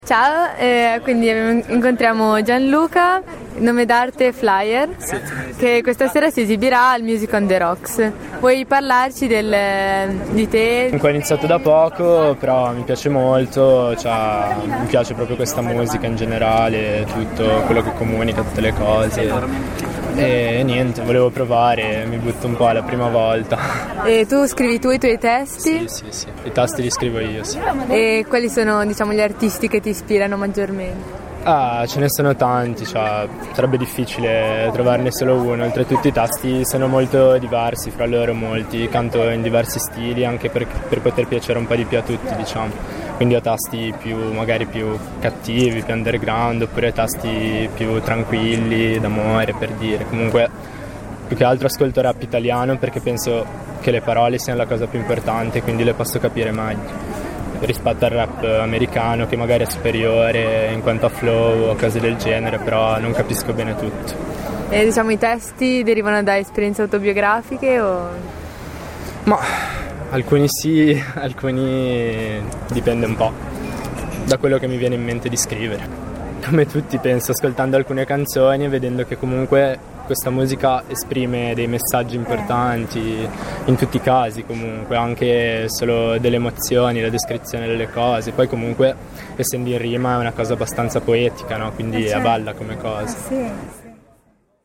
Intervista
Intervista realizzata dalla nostra TWR nell'ambito del concerto "Rock on the beach" tenutosi a Zoagli il 29 Agosto 2013.